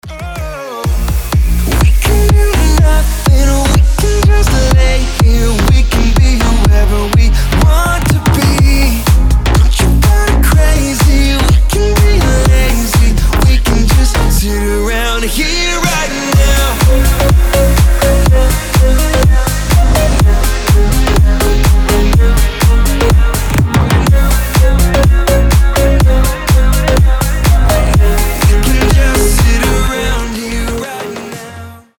• Качество: 320, Stereo
громкие
EDM
future house
энергичные
Зажигательный future house